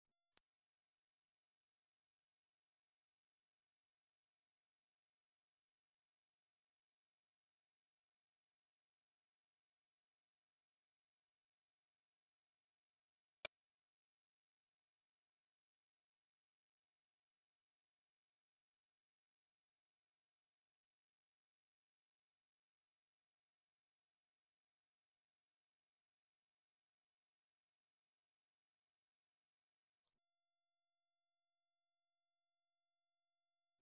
Ультра звук на мобилу для отпугивания собак